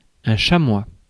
Ääntäminen
Synonyymit jaune isard ocre Ääntäminen France: IPA: /ʃa.mwa/ Haettu sana löytyi näillä lähdekielillä: ranska Käännös Substantiivit 1. ībex {m} Muut/tuntemattomat 2.